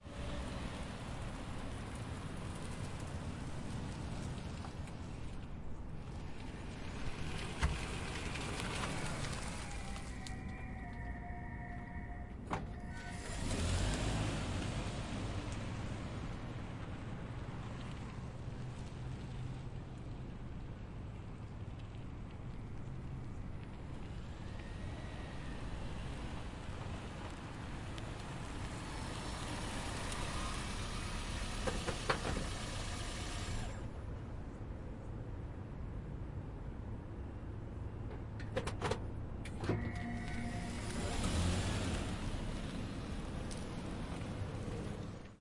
汽车 " 停止引擎
描述：将面包车丰田花冠2.0的发动机停下来用Zoom H1录制的
声道立体声